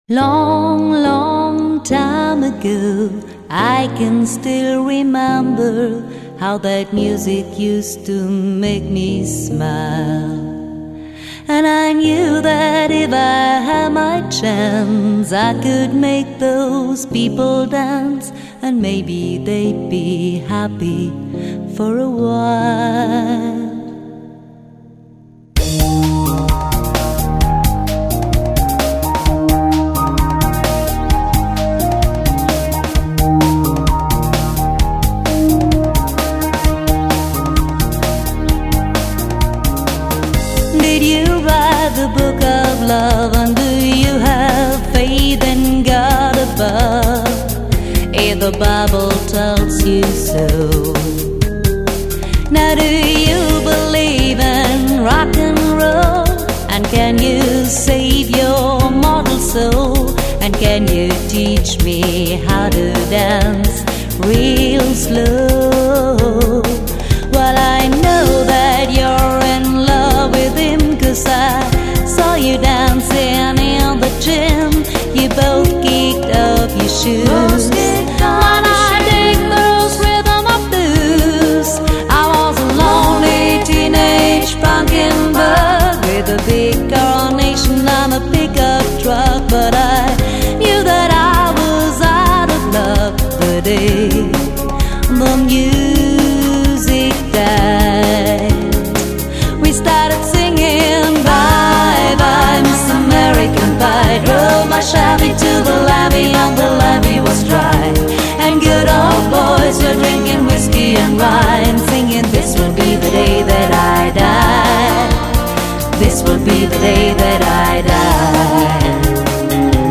2-3köpfige professionelle Tanz- und Showband
• Allround Partyband
• Coverband